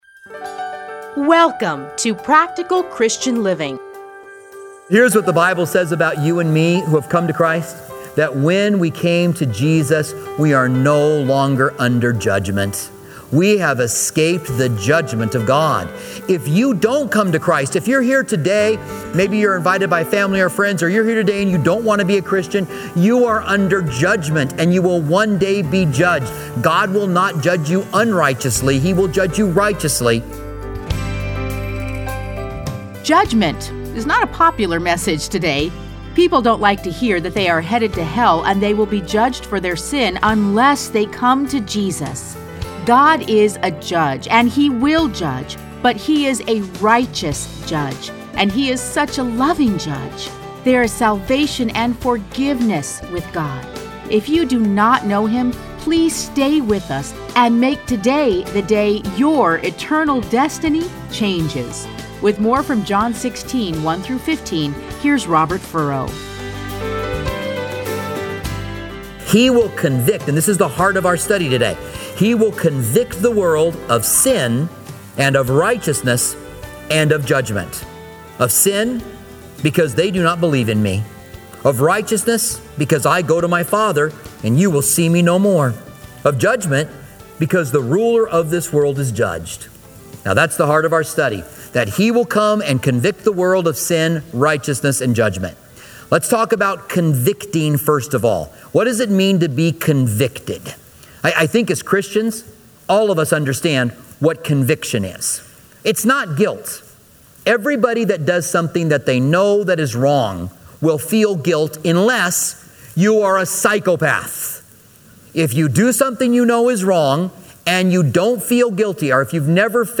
Listen to a teaching from John John 16:1-15 .